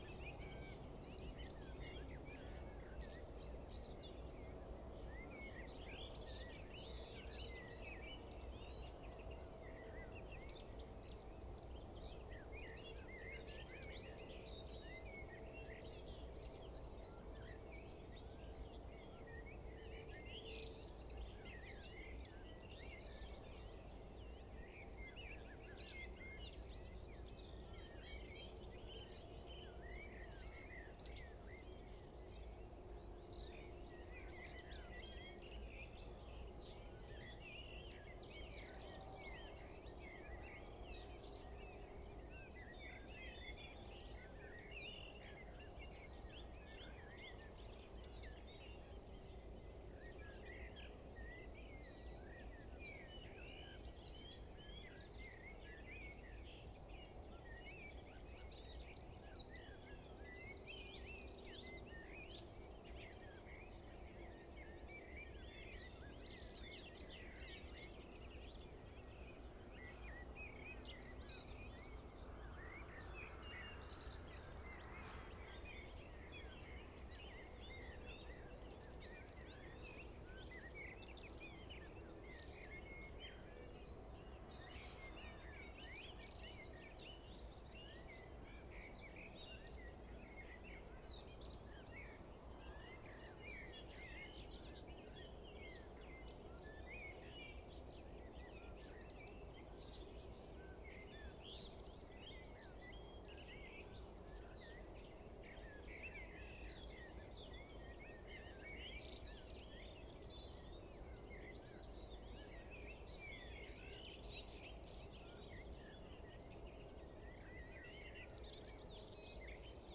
Picus viridis
Corvus corone
Sylvia curruca
Phylloscopus collybita
Emberiza citrinella
Sylvia atricapilla
Alauda arvensis
Turdus merula
Turdus philomelos
Falco tinnunculus
Troglodytes troglodytes
Prunella modularis
Erithacus rubecula